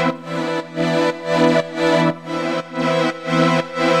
GnS_Pad-MiscA1:4_120-E.wav